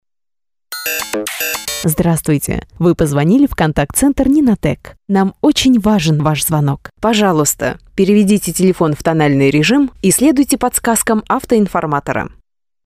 IVR- классическая Категория: Аудио/видео монтаж